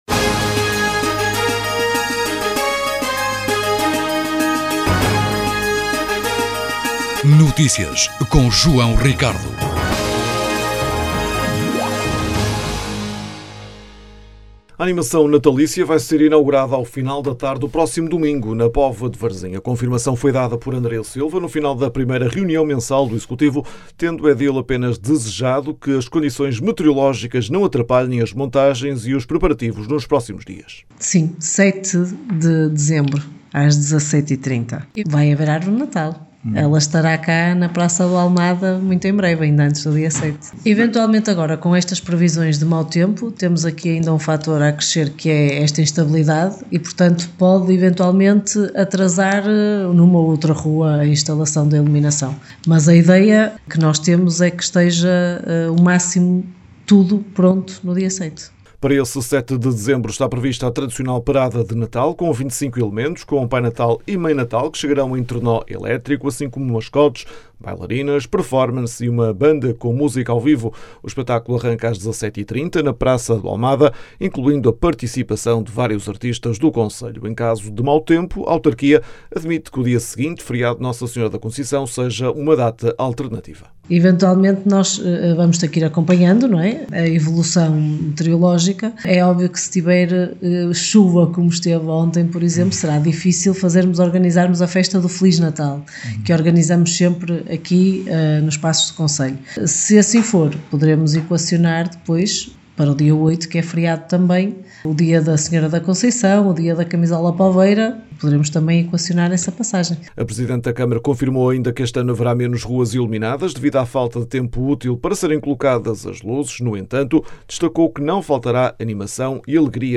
O atraso na abertura do Natal na Póvoa de Varzim foi questionado pelos partidos da oposição na reunião do executivo.
A edil defendeu que esta ação não foi um contributo positivo para a Póvoa de Varzim. As declarações podem ser ouvidas na edição local.